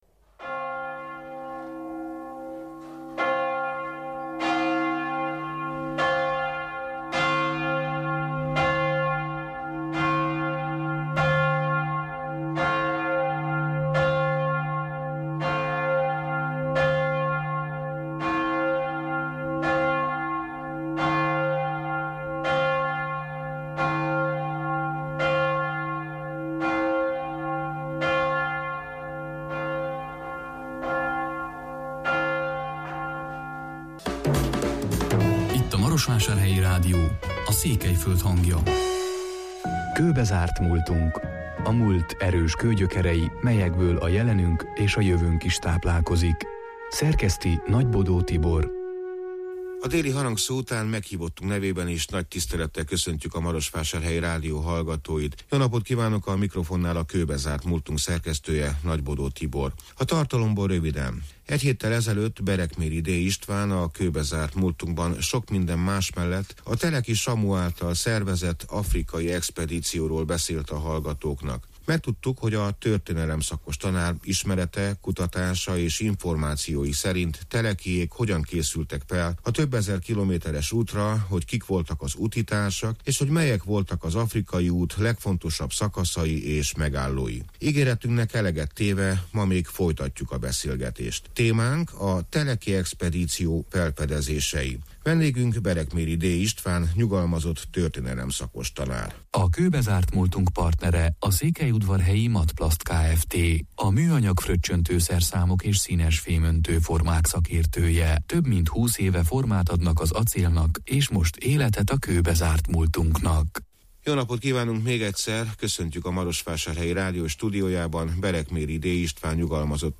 Ígéretünknek eleget május 18-án, a déli harangszó után, A teleki expedíció felfedezései témakörben még folytattuk a beszélgetést.